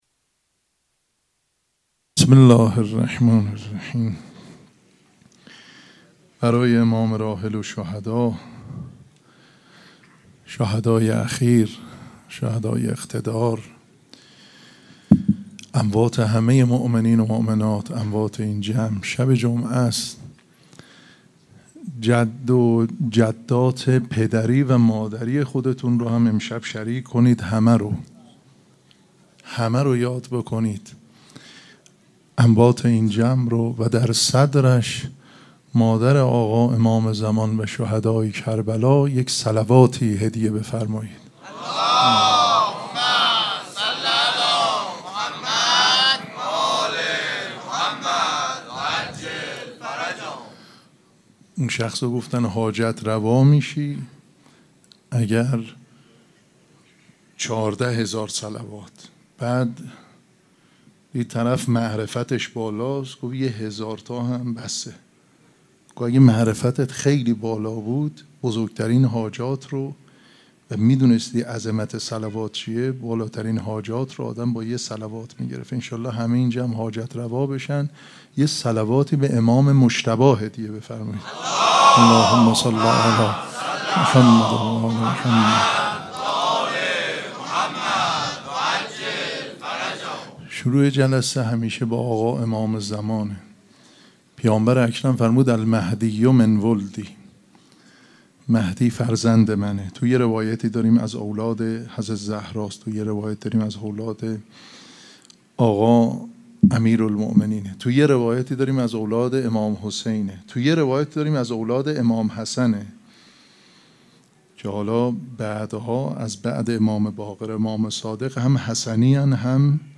سخنرانی
مراسم عزاداری شب شهادت امام حسن مجتبی(ع) پنجشنبه ۹ مرداد ۱۴۰۴ | ۶ صفر ۱۴۴۷ ‌‌‌‌‌‌‌‌‌‌‌‌‌هیئت ریحانه الحسین سلام الله علیها